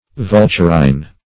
Vulturine \Vul"tur*ine\ (?; 277), a. [L. vulturinus.]